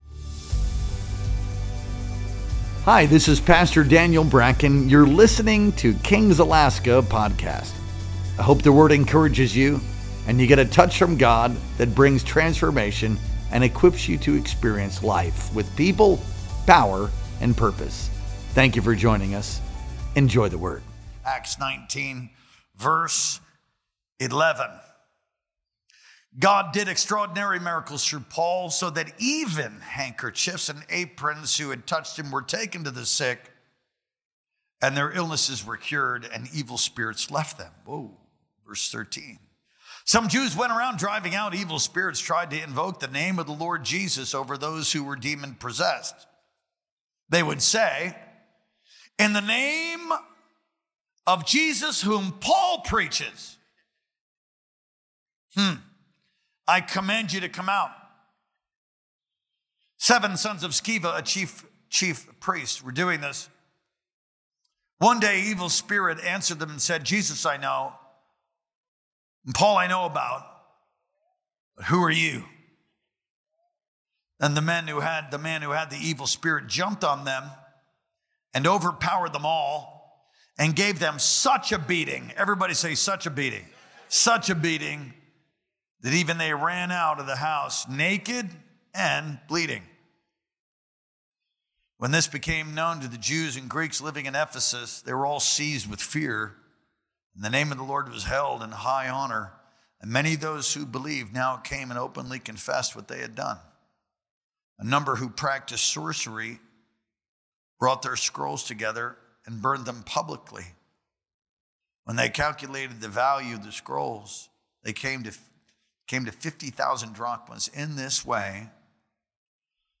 Our Sunday Night Worship Experience streamed live on October 19th, 2025.